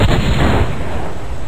a stick of dynamite1.ogg